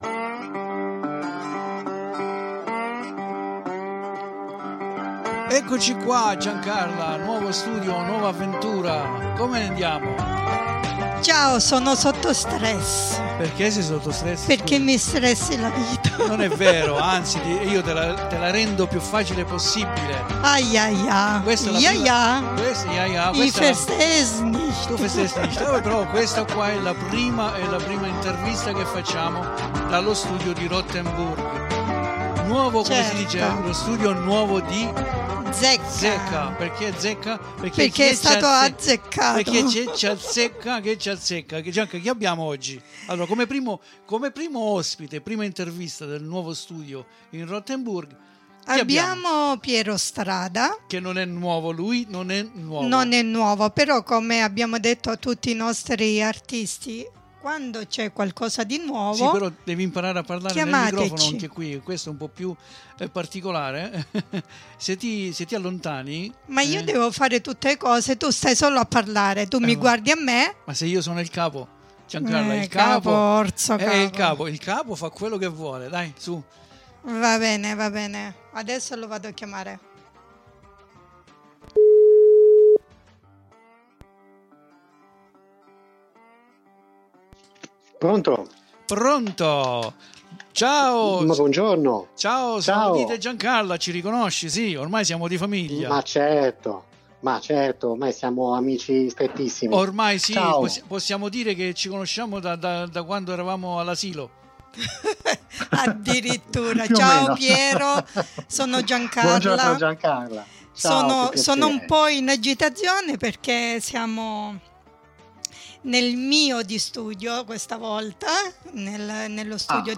QUINDI VI INVITO AD ASCOLTARE LA SUA INTERVISTA CONDIVISA QUI IN DESCRIZIONE E ALLEGO INOLTRE ANCHE UN LINK YOUTUBE DOVE VEDERE E ASCOLTARE ALTRI SUOI BRANI , IN ATTESA DI UN ALTRO VIDEO STRAORDINARIO!